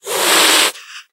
hiss2.mp3